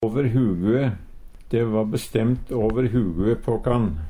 åver hugue - Numedalsmål (en-US)